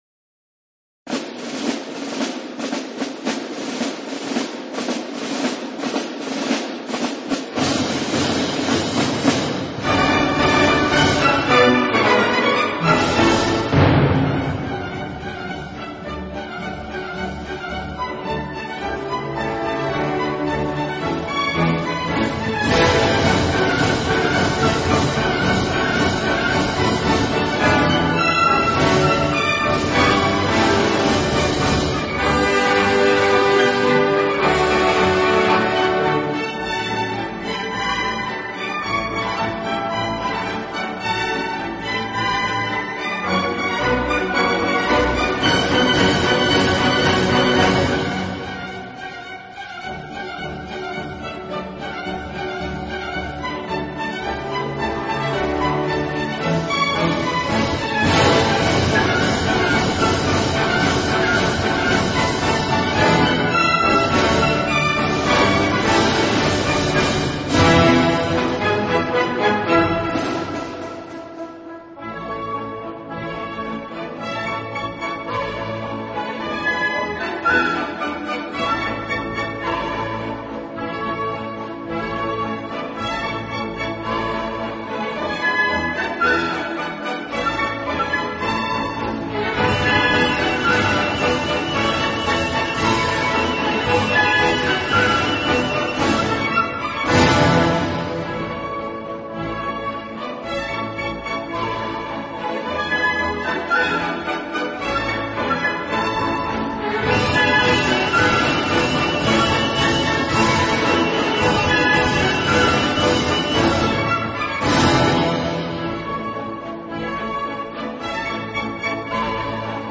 音乐类型：Classic 古典
音乐风格：Classical,Waltz
的演出方式，自己边拉小提琴边指挥乐队。